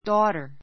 dau gh ter 中 A1 dɔ́ːtə r ド ータ （ ⦣ gh は発音しない） 名詞 複 daughters dɔ́ːtə r z ド ータ ズ 娘 むすめ my oldest [eldest] daughter my oldest [ 英 eldest] daughter 私の長女 my only daughter my only daughter 私の1人娘 This is my daughter Ann.